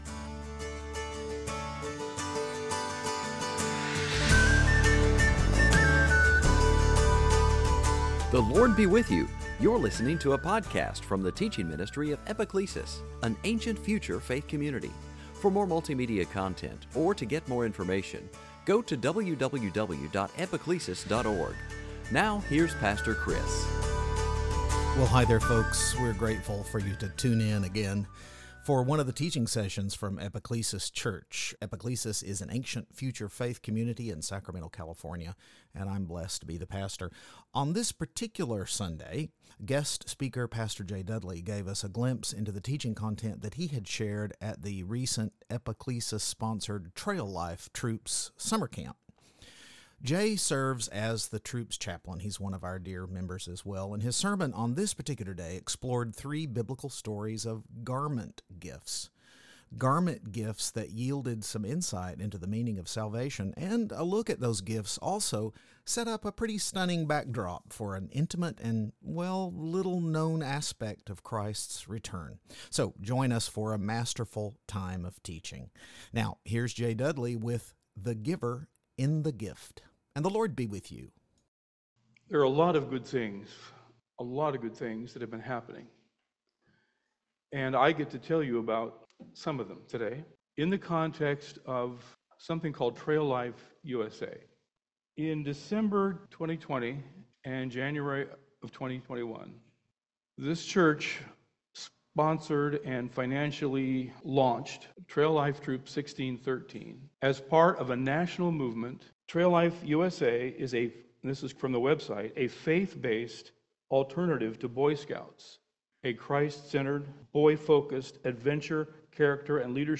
Series: Sunday Teaching
Service Type: Season after Pentecost